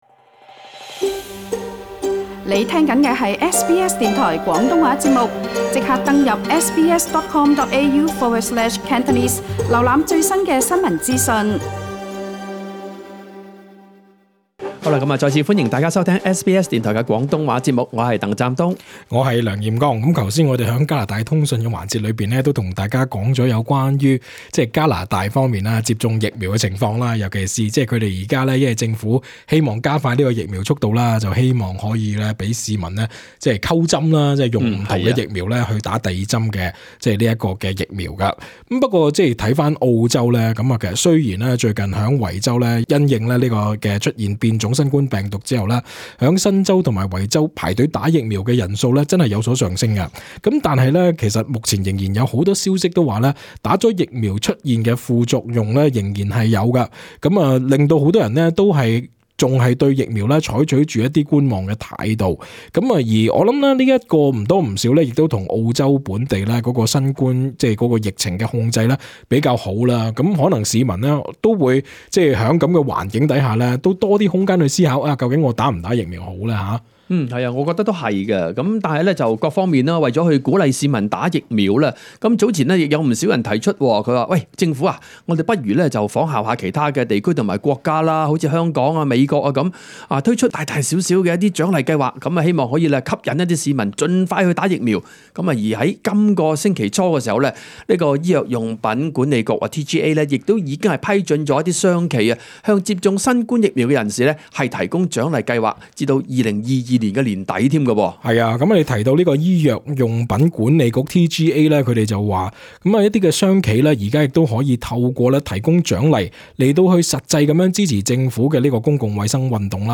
接聽了多位聽眾的來電，發表他們的個人意見。